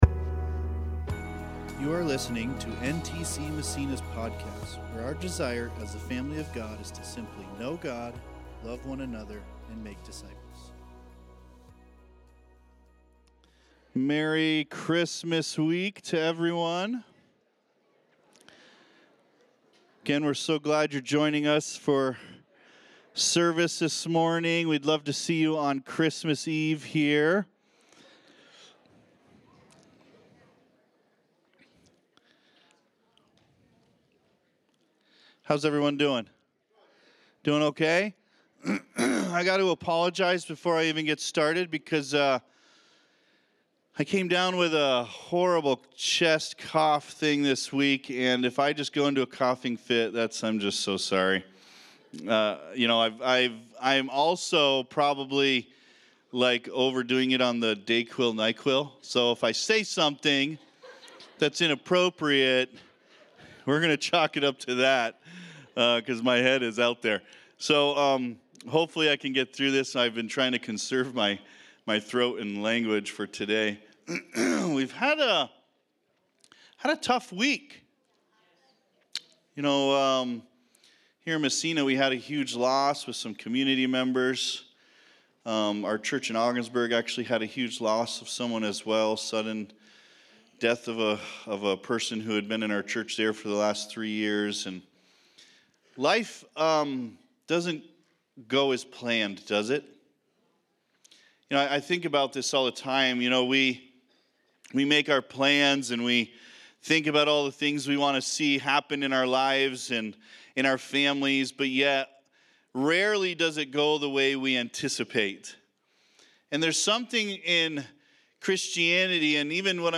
Christmas Service!